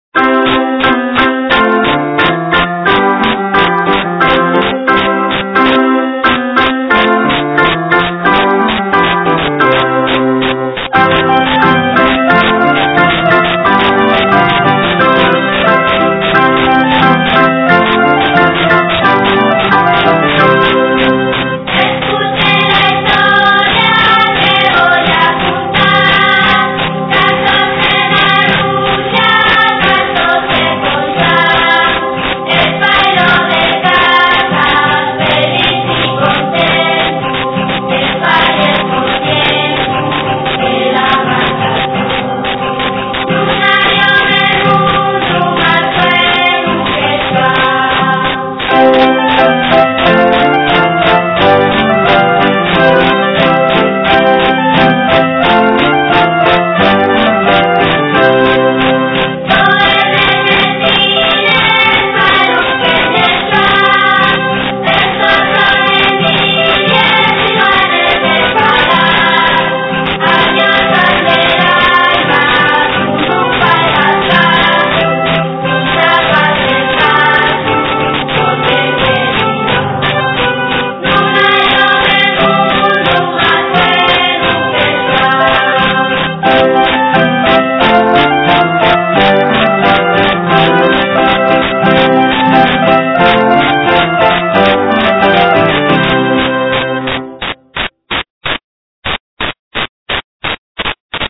De xuru,que la lletra conozla fasta la vuesa güela porque son cancios de la tradición musical asturiana recoyíos munchos d'ellos del cancioneru musical d'Eduardo Martínez Torner.
Los que canten son los neños de los colexos públicos de Lieres y Sariegu y pa que-yos salga tan bien tienen qu'ensayar dos hores a la selmana